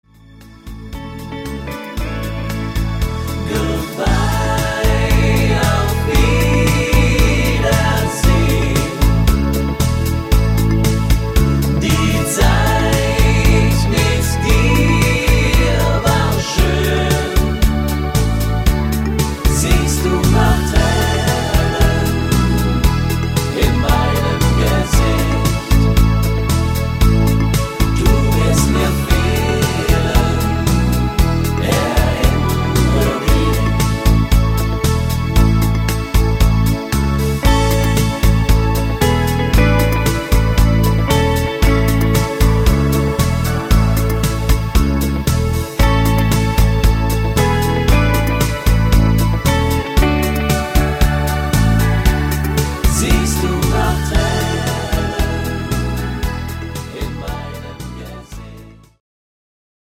Medium Beat Mix